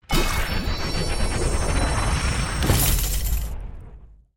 safebox_gold_fly.mp3